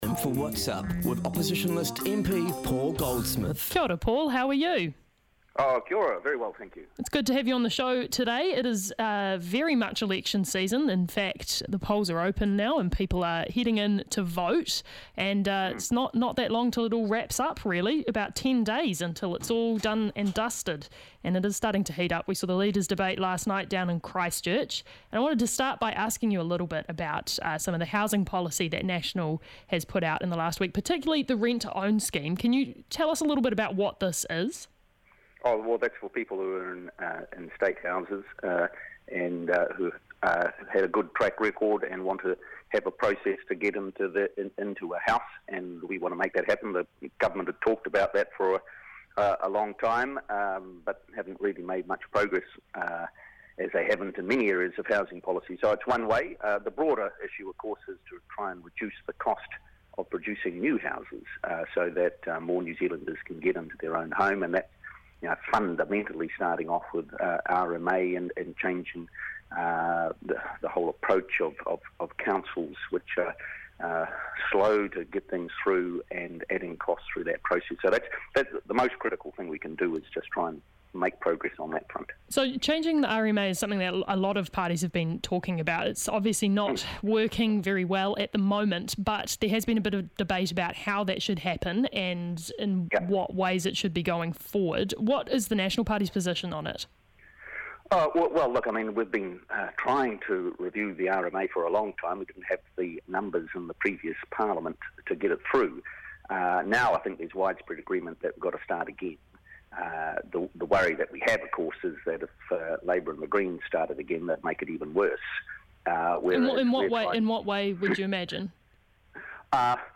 National MP Paul Goldsmith joins us for some early morning politics. Covering National's Housing policy, their internal communications around policy, and their Economic Development policy.